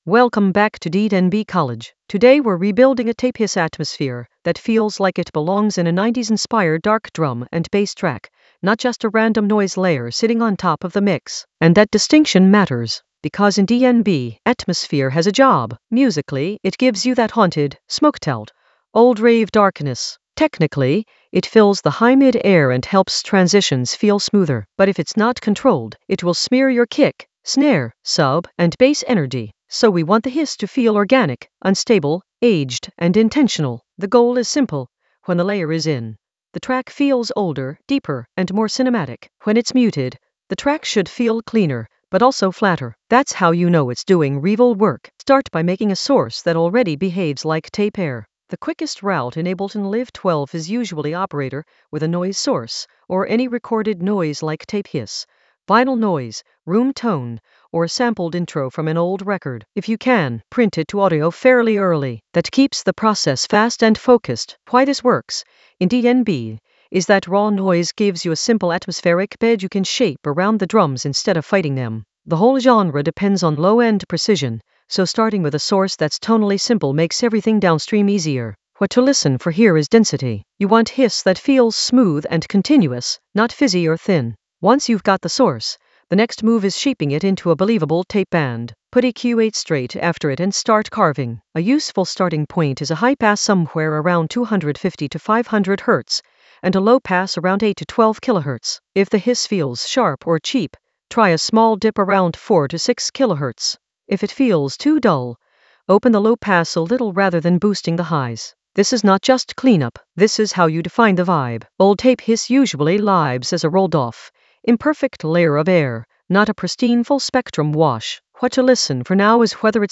An AI-generated intermediate Ableton lesson focused on Rebuild a tape-hiss atmosphere for 90s-inspired darkness in Ableton Live 12 in the Sampling area of drum and bass production.
Narrated lesson audio
The voice track includes the tutorial plus extra teacher commentary.